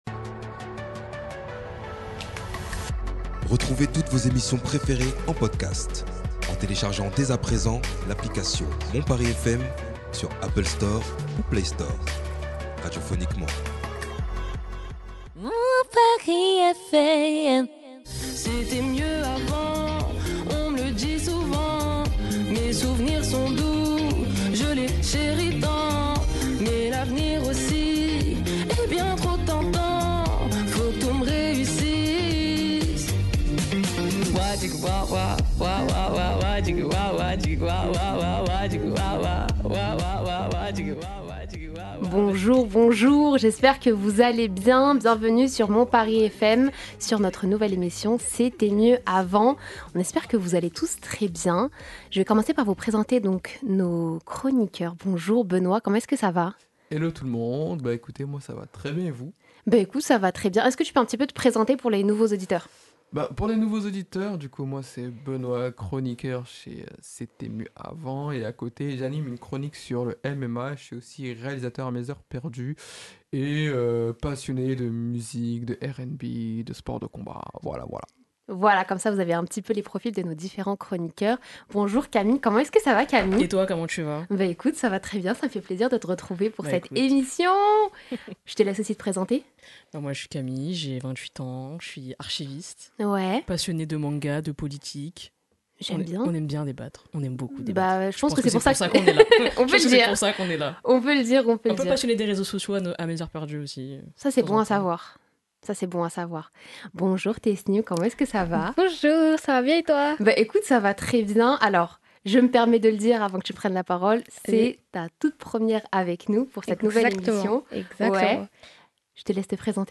Le débat se concentre afin de savoir si les nouveaux divertissements ayant émergés avec les nouvelles technologies sont mieux que les divertissements que nous avons pu connaître avant la forte influence de l’air numérique.